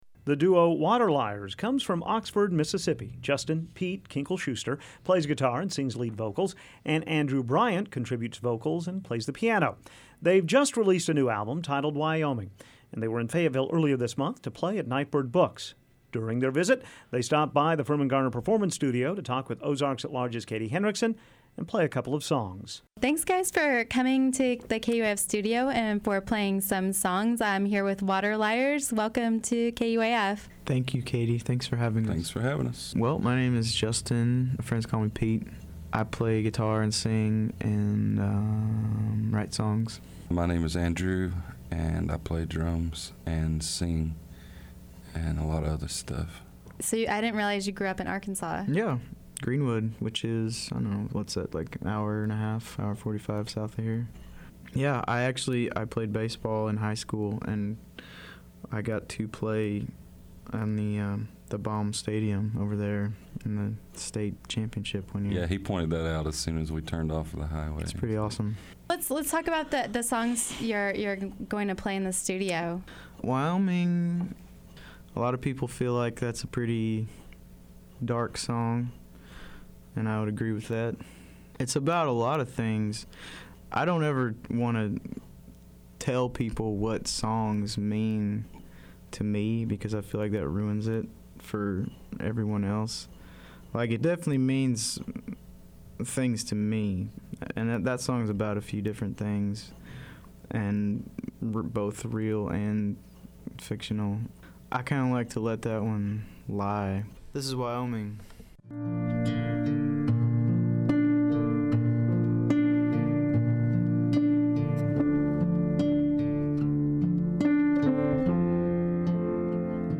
guitar/lead vocals
percussion, piano, vocals